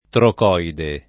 trocoide [ trok 0 ide ]